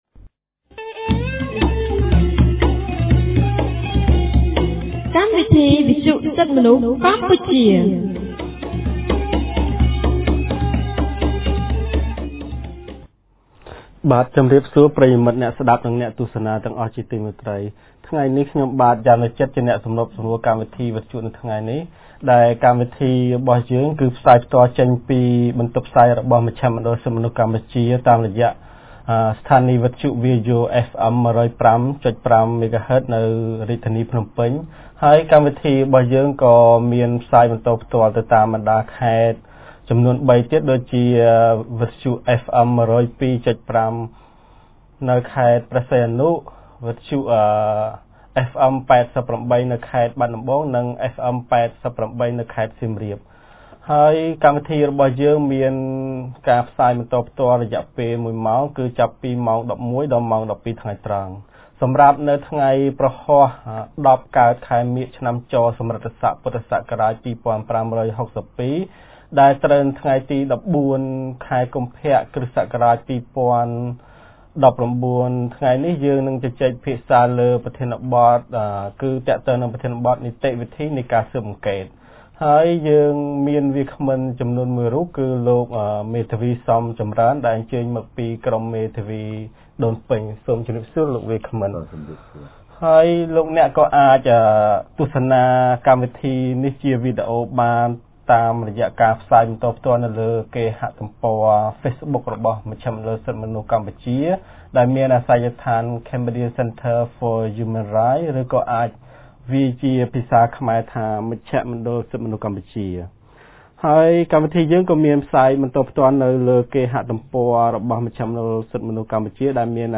On 14 February 2019, CCHR’s Fair Trial Rights Project (FTRP) held a radio program with a topic on Procedure of Police Inquiry.